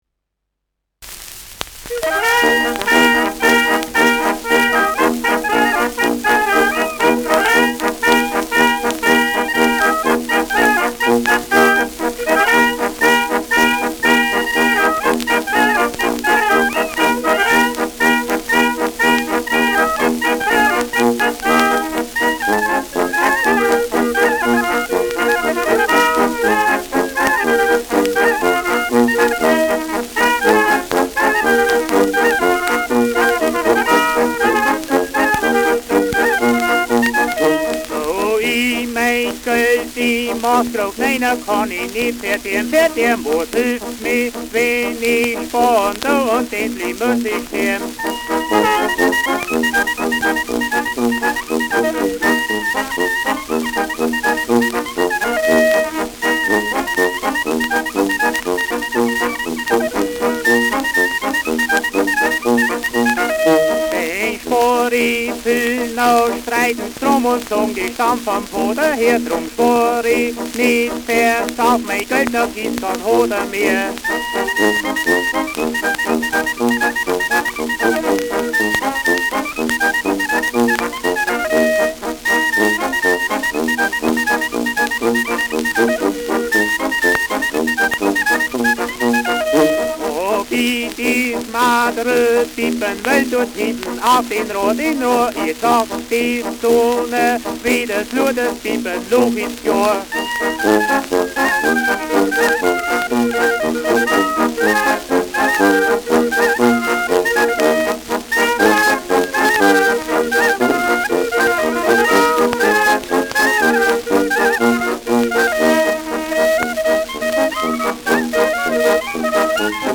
Pimperl-Galopp : mit Gesang
Schellackplatte
präsentes Rauschen : gelegentliches Knistern : vereinzeltes Knacken
Kapelle Die Alten, Alfeld (Interpretation)